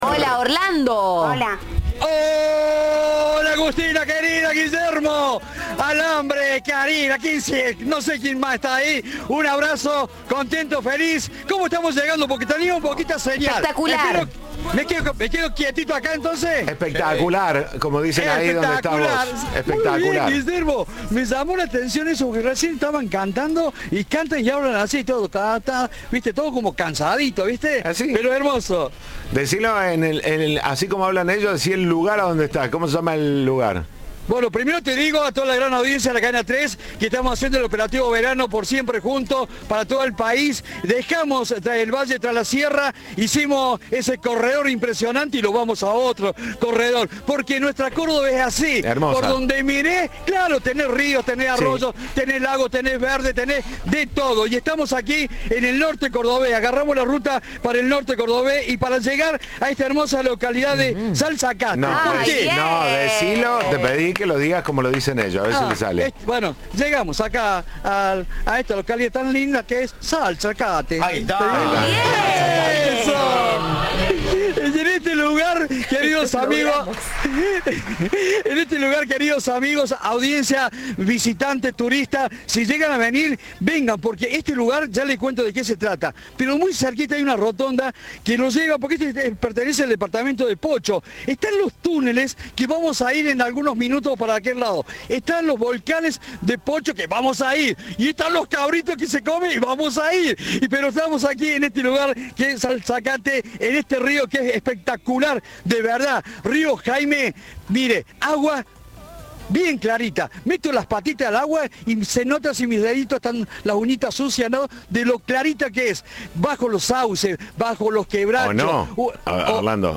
Cadena 3 visitó la localidad cabecera del departamento Pocho, donde se realizan todo tipo de productos comestibles, desde cabrito a queso de cabra con arrope de algarroba y de miel.